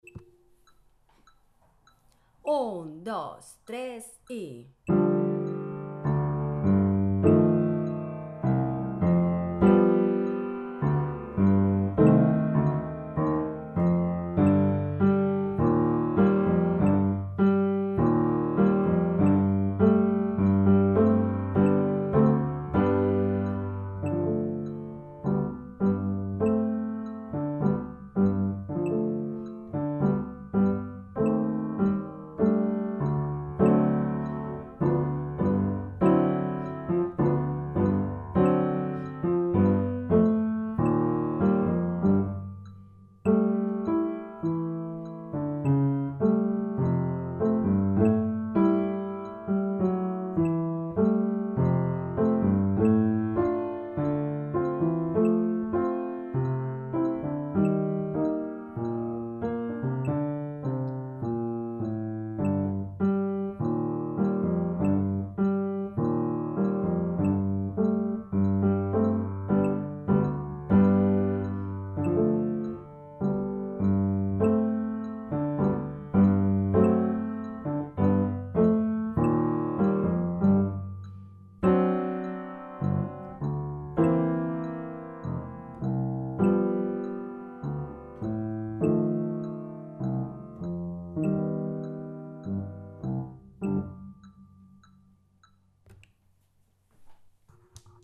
De cada una de ellas hay un audio con la música del piano que va a acompañaros y otro audio con la canción tocada por el violín.